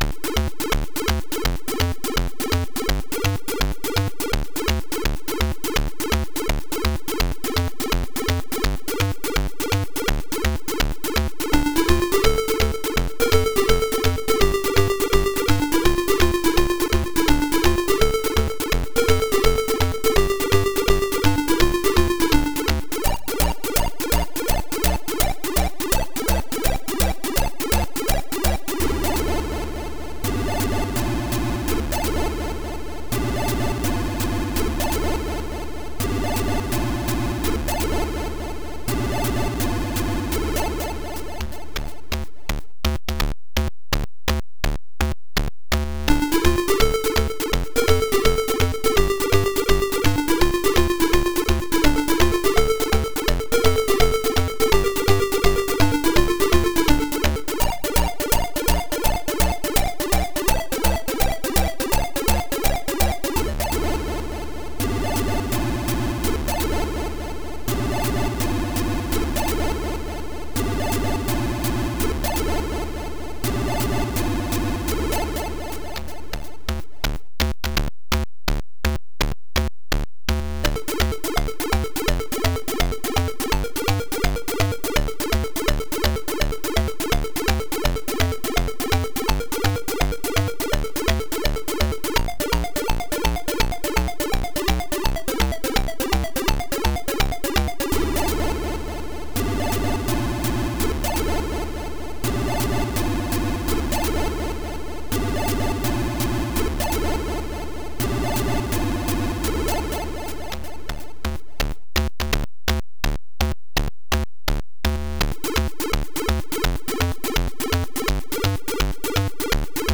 • Chip music
• Music requires/does smooth looping